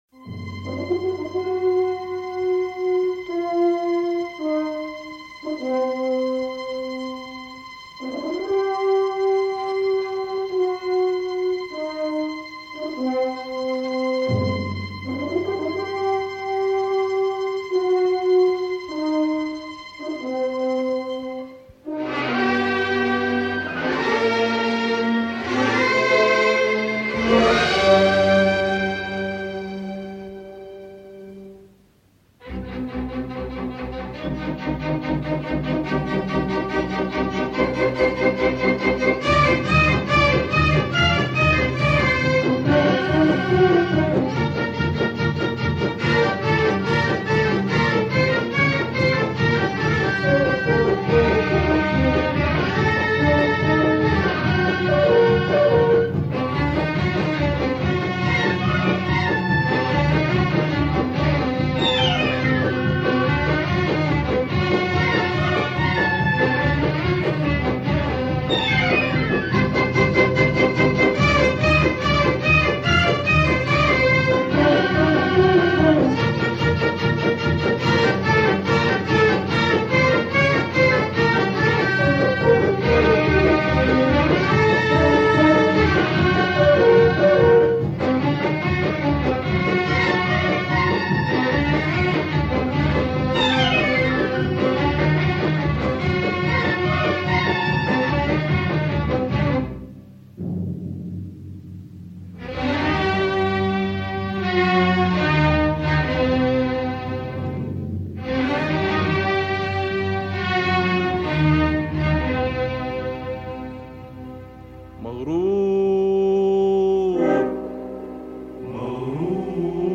Light surface marks, still ok.